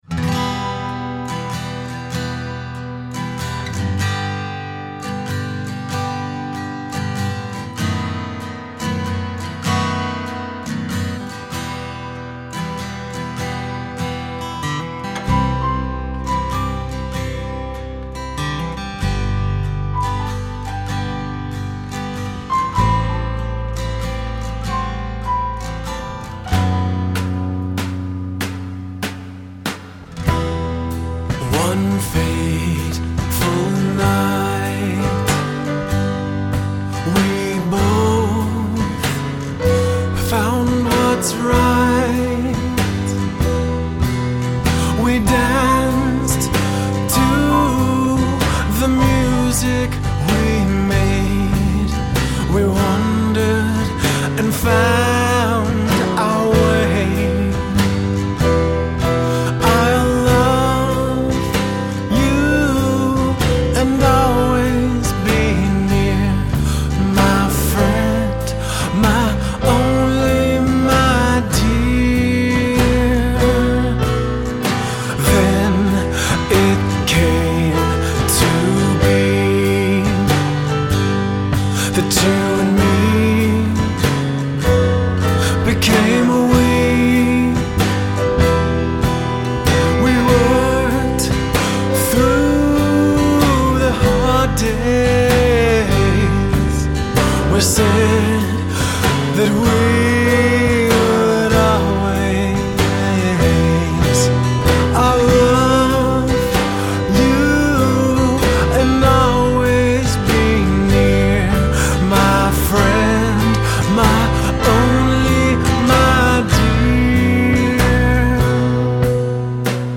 Guitar, Vocals
Drums
Bass Guitar
Piano